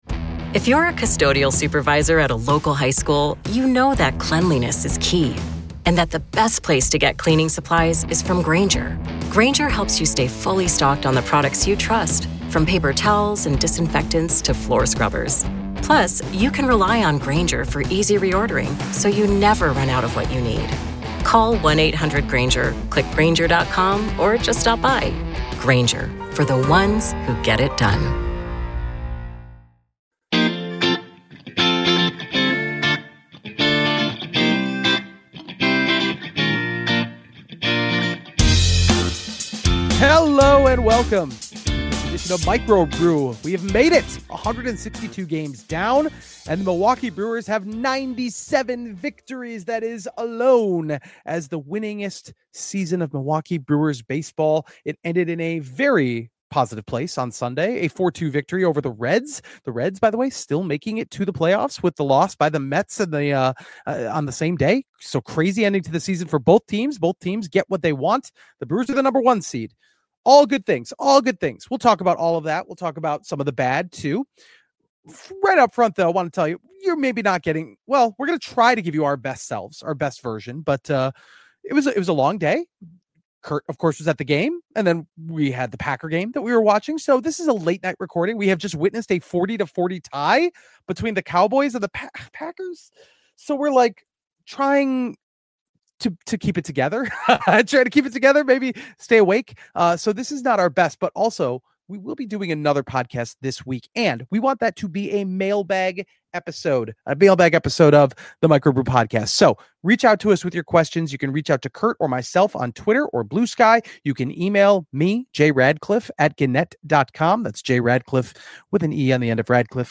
Plus: A conversation with Sal Frelick (09.29.2025)